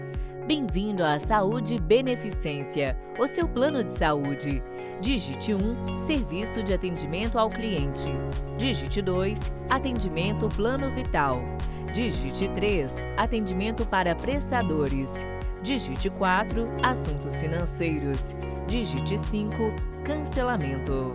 A gravação tem que contem uma musica inicial e no final !!!!